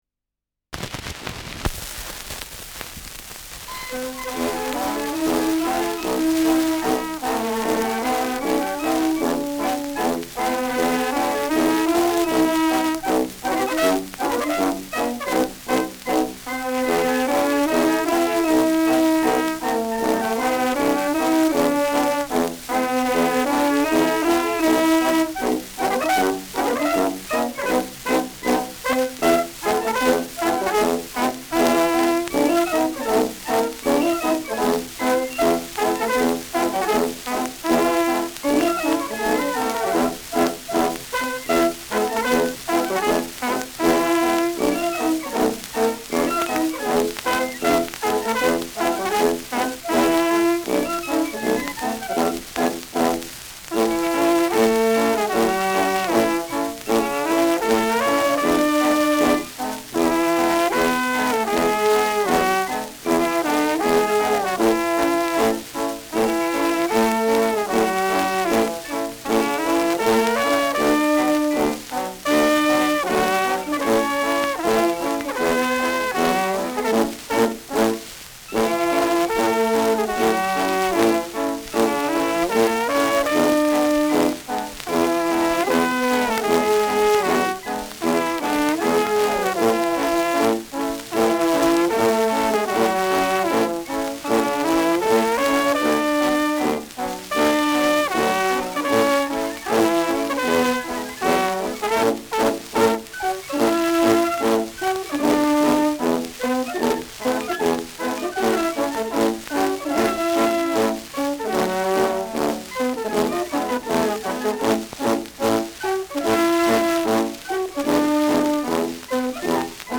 Schellackplatte
Stärkeres Grundrauschen : Gelegentlich leichtes Knacken